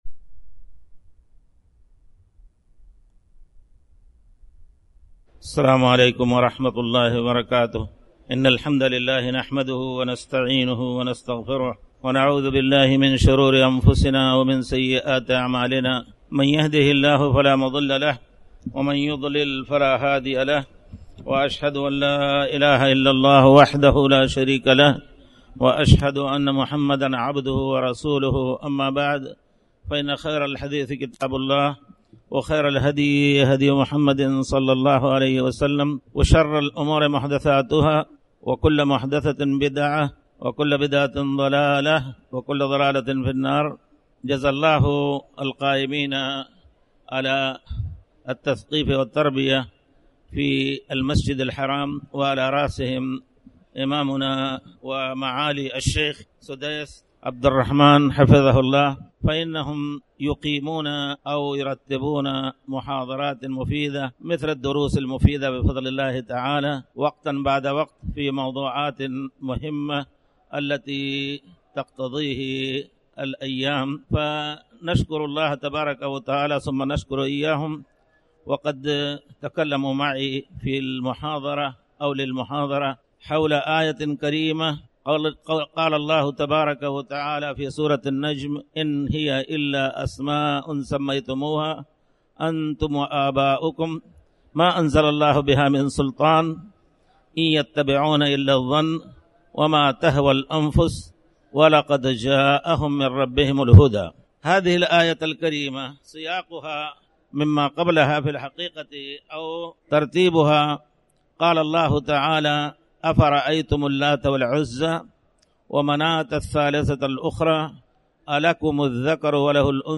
تاريخ النشر ٢٦ رجب ١٤٣٩ المكان: المسجد الحرام الشيخ